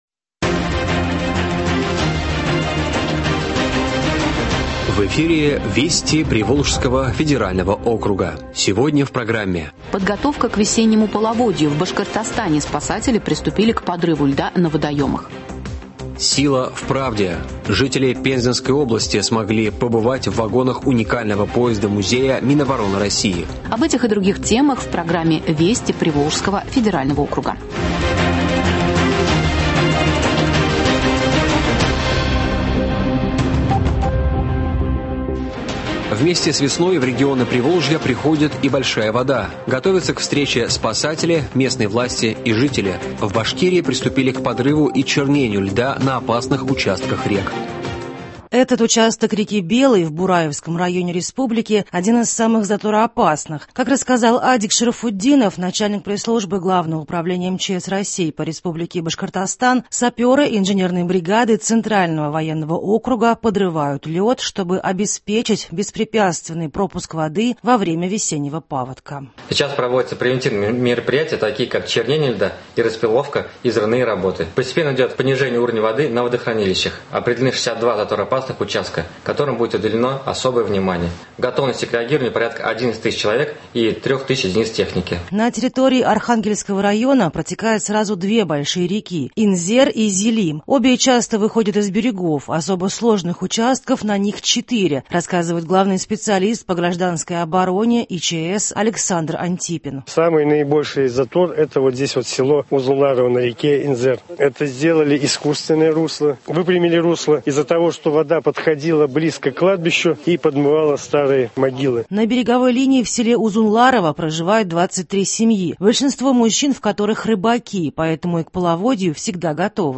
Радиообзор событий недели в региона ПФО.